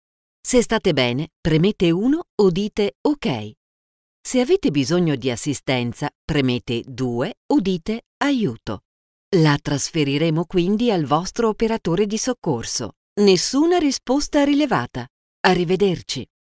Dubbing voice over samples
Italian voices